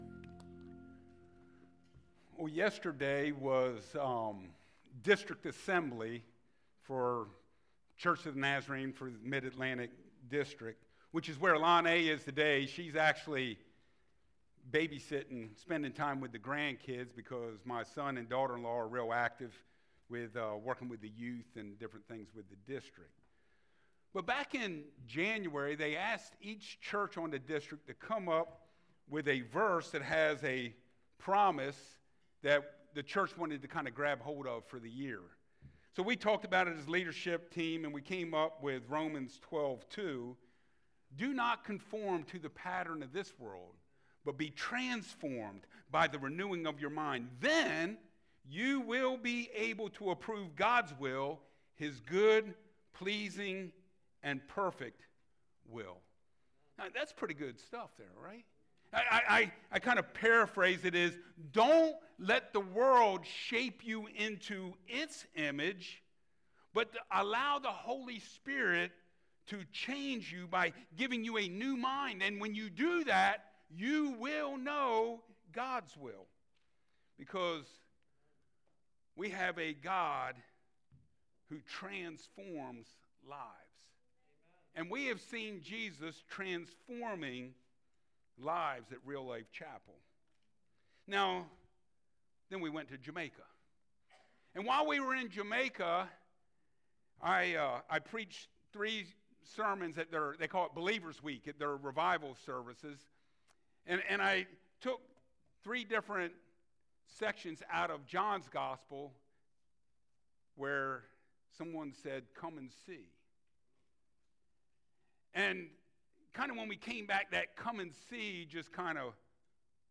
John 1:38-39a Service Type: Sunday Mornings How God Moves Through His People
Topics: Testimonies